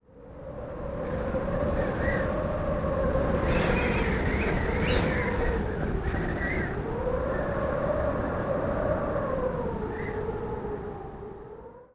sfx_wind_high.wav